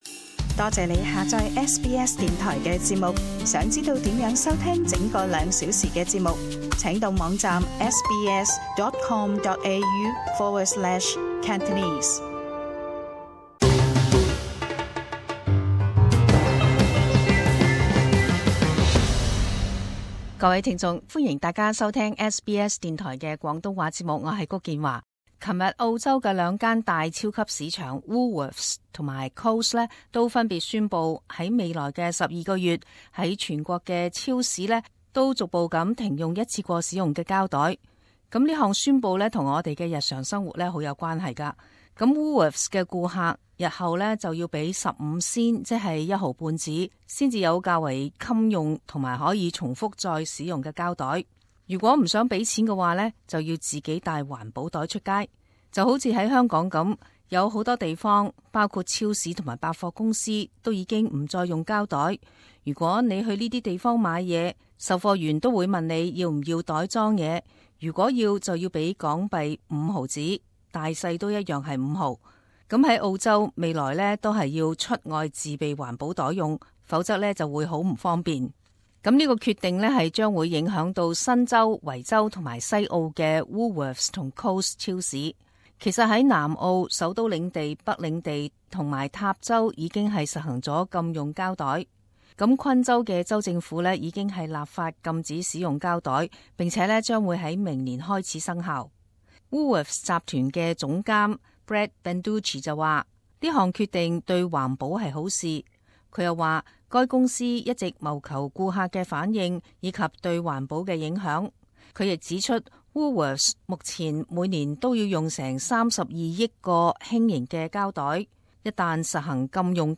【時事報導】 澳洲兩大超市宣佈逐步停用免費膠袋